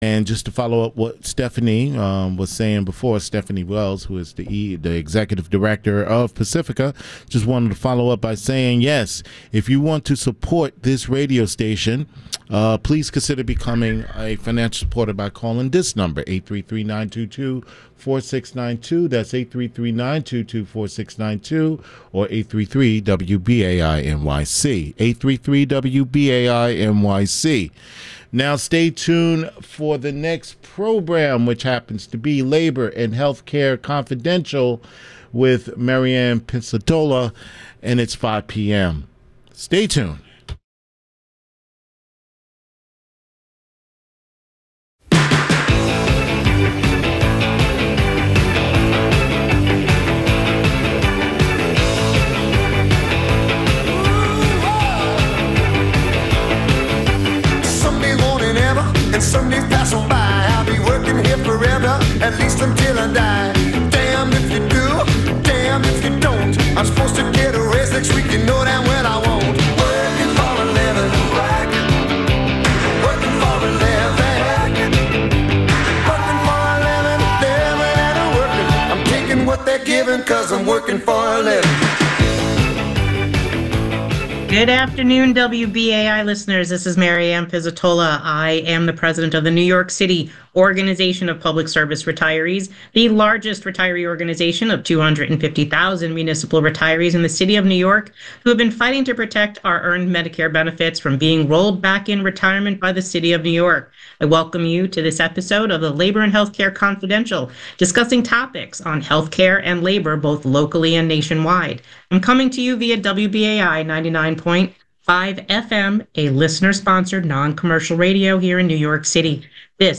Today I was LIVE on LABOR AND HEALTHCARE CONFIDENTIAL WBAI 99.5 FM NYC